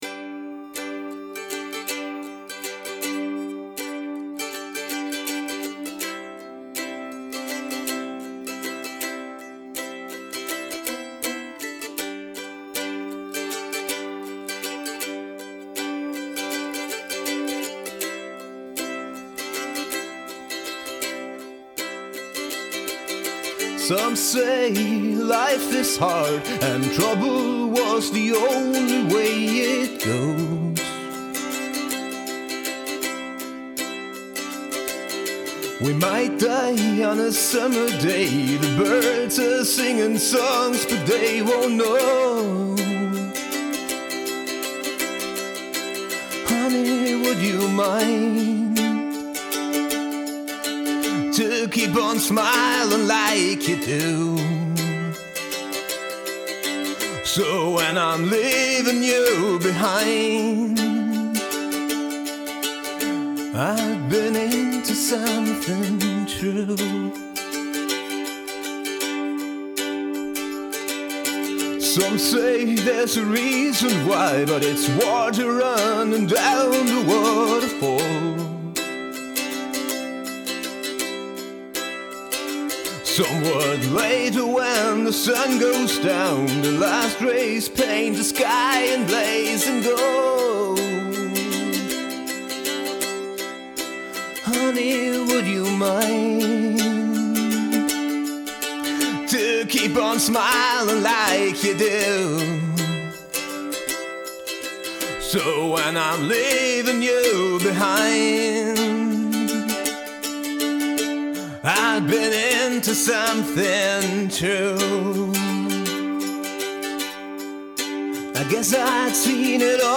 Hier teile ich nochmal meinen bisher einzigen Song mit Mandoline.